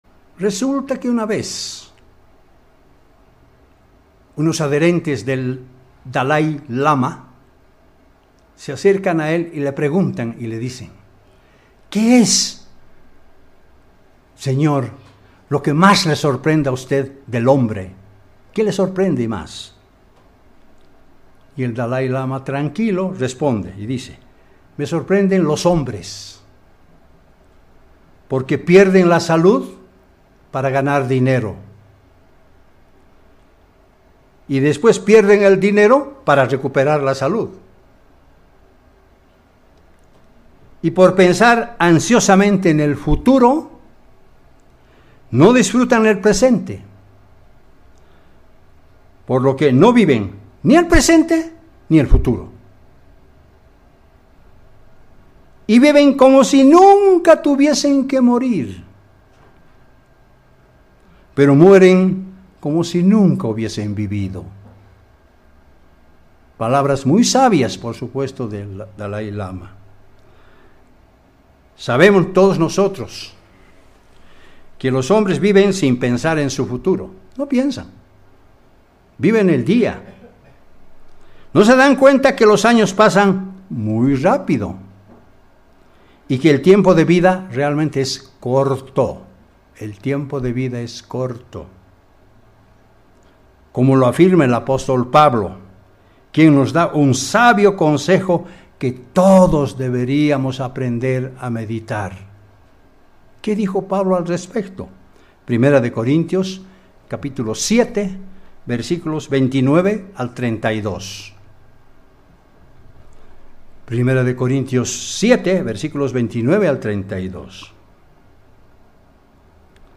Given in La Paz